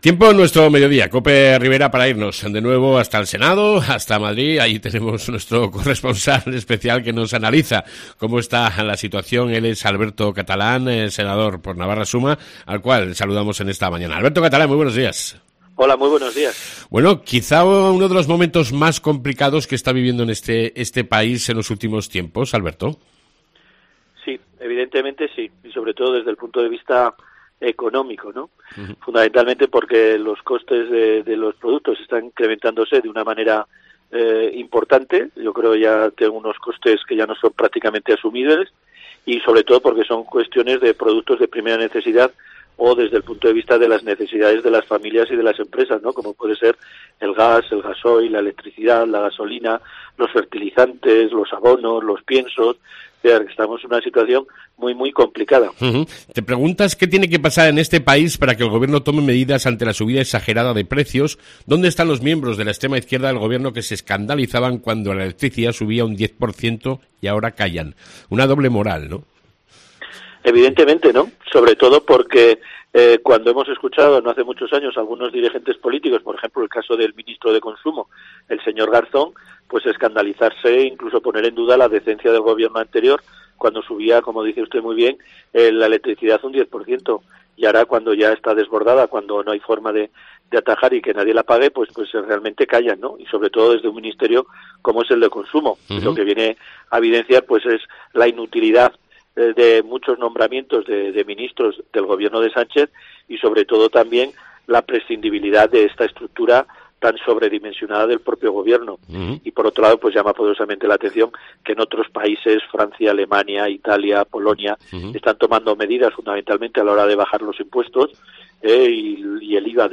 Hablamos con el Senador de UPN Alberto Catalán